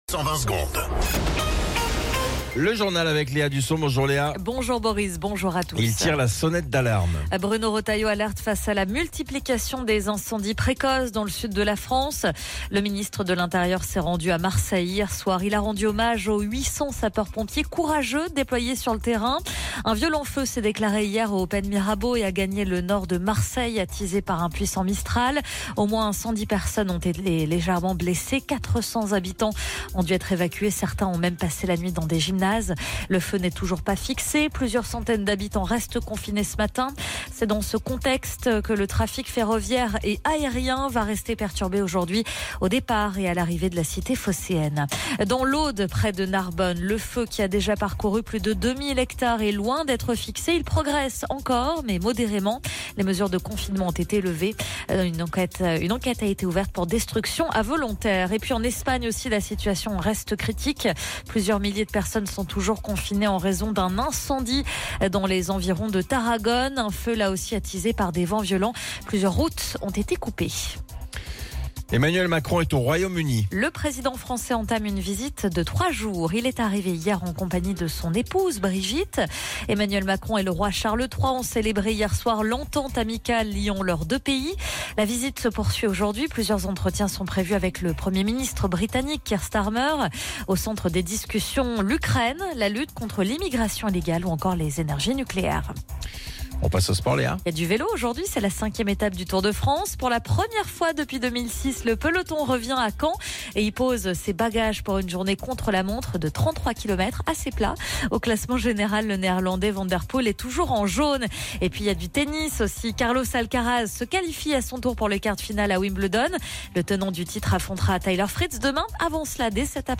Flash Info National 09 Juillet 2025 Du 09/07/2025 à 07h10 .